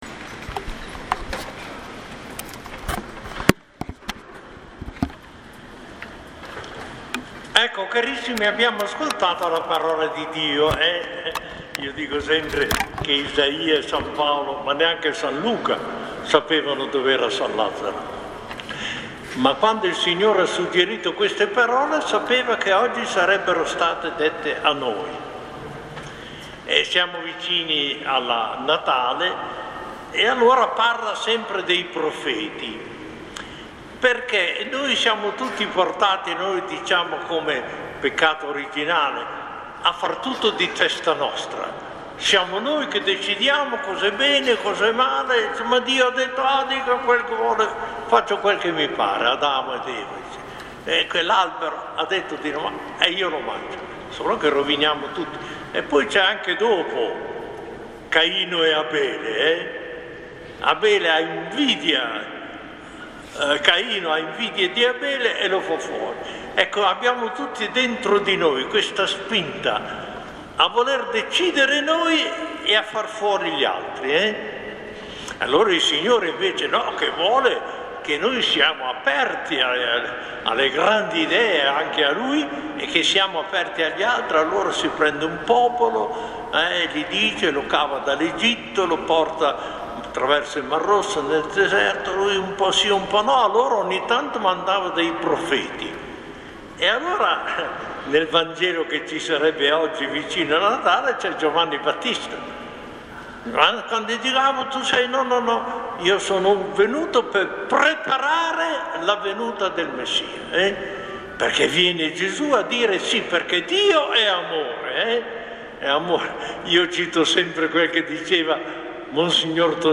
Il 17 dicembre 2017 Mons. Luigi Bettazzi ha celebrato la S. Messa nel giorno della festa di San Lazzaro.
Viene riportato l’audio dell’omelia.